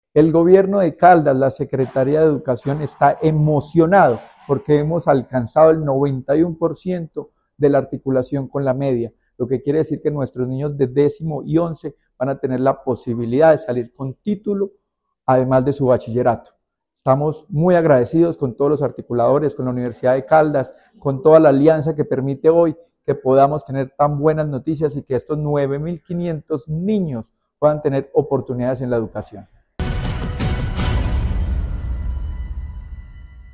Descargar Video Descargar Audio Luis Herney Vargas Barrera, secretario de Educación de Caldas. 0